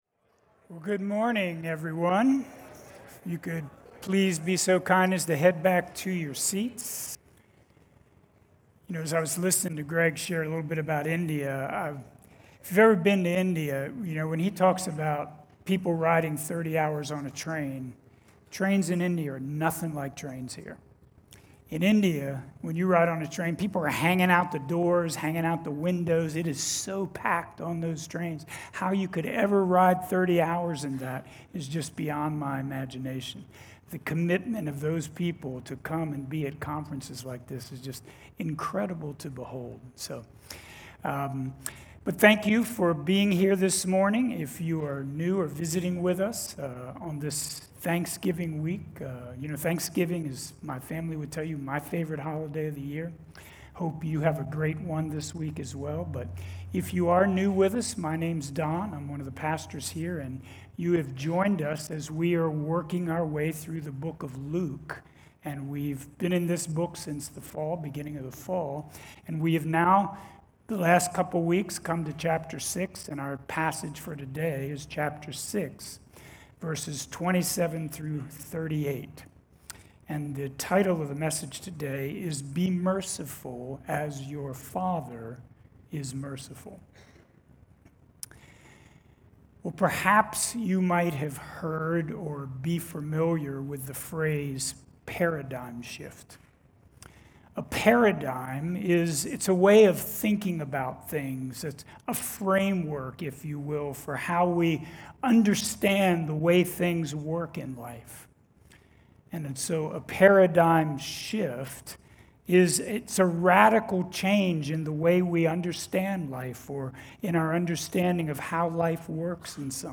Messages from Grace Community Church in Kingsville, MD